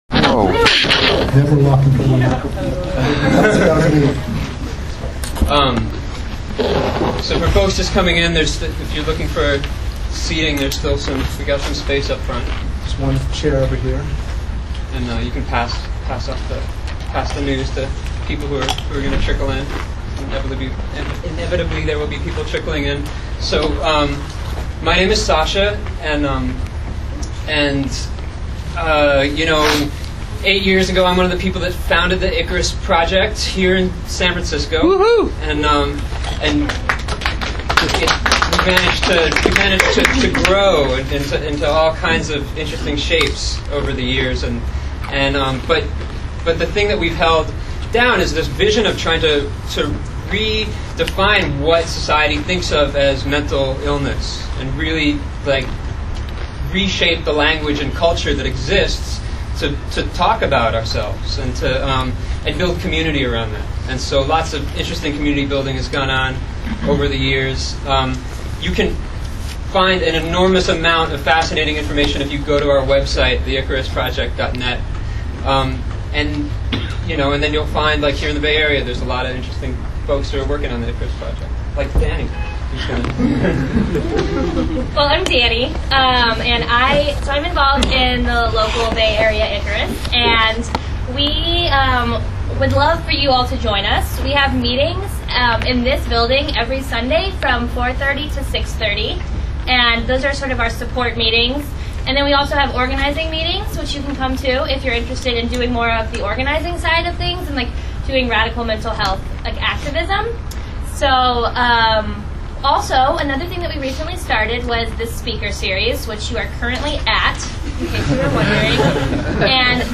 It was a lively discussion which you can listen to here (you might have to download Quicktime 7 if it doesn't work -- sorry for the technical difficulties!)